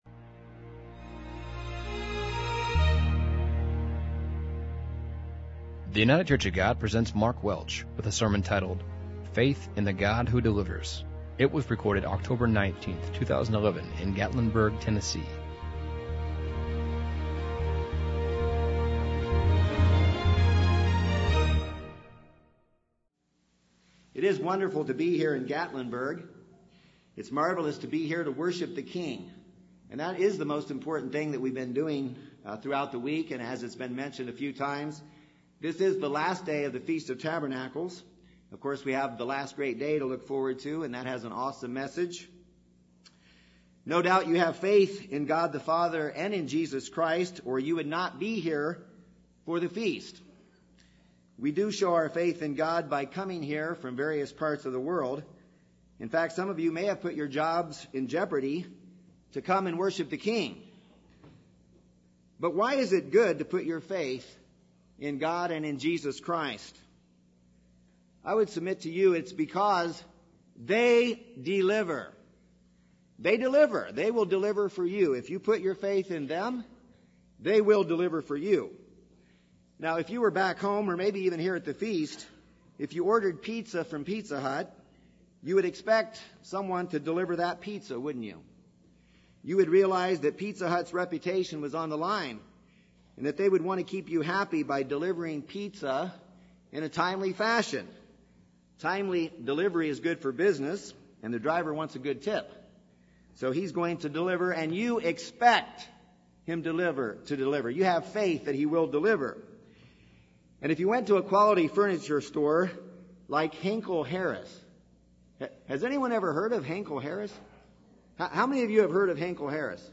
2011 Feast of Tabernacles sermon from Gatlinburg, Tennessee.
This sermon was given at the Gatlinburg, Tennessee 2011 Feast site.